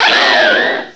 sovereignx/sound/direct_sound_samples/cries/braviary.aif at master
braviary.aif